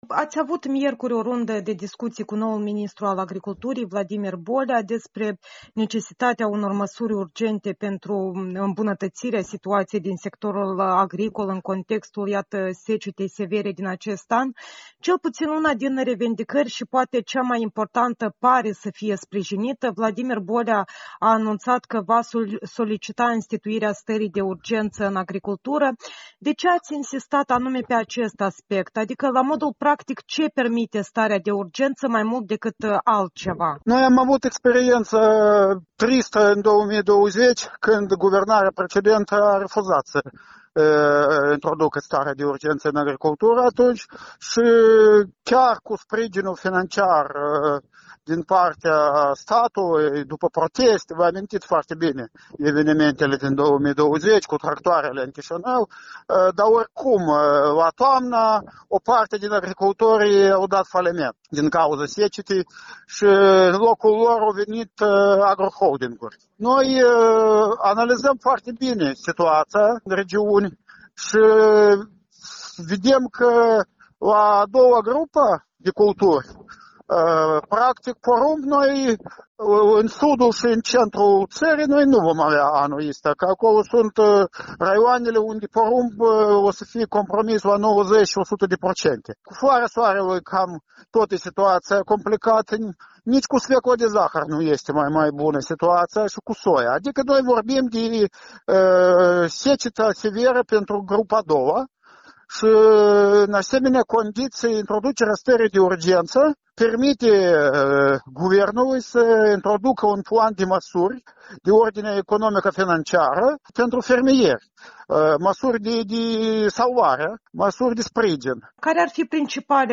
Interviu cu Alexandru Slusari, directorul executiv al asociației „Forța Fermierilor”